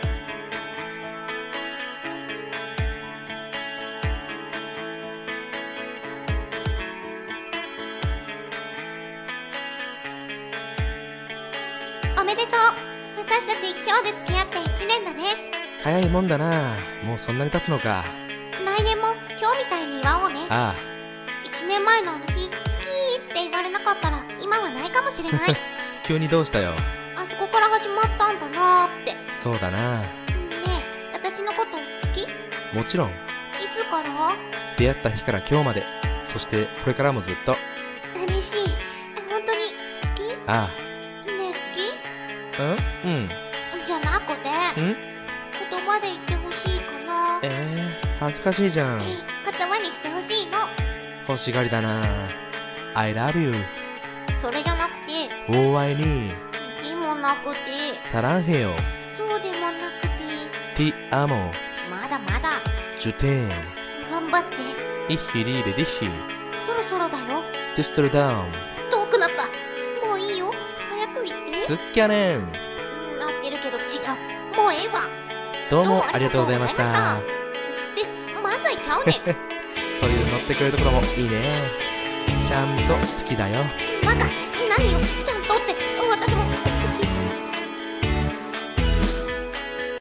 3人声劇】アオハルかよ